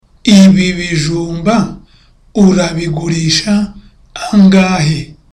(Loudly)